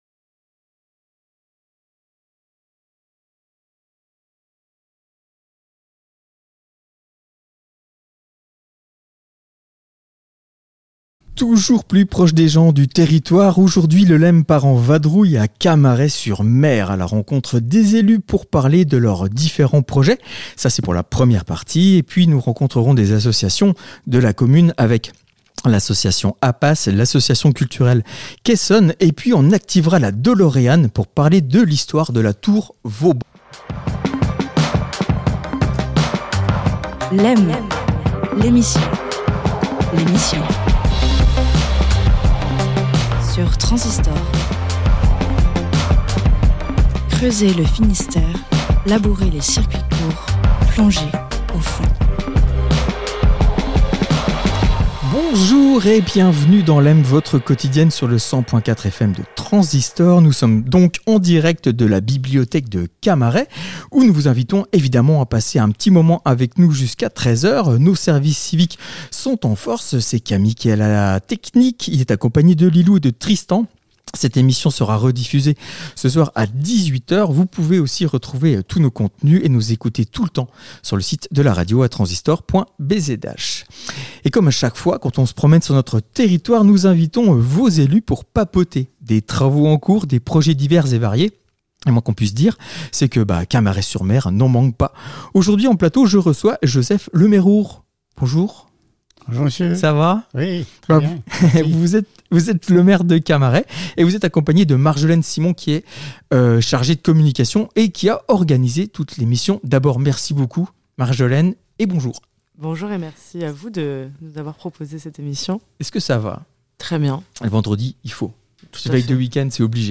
Nous allons à la rencontre de vos élus et de vos associations et pour cette 9ᵉ édition, nous sommes à Camaret-sur-Mer à la rencontre de M. le maire, Joseph Le Merour pour parler de la commune après trois ans de blocage de son développement.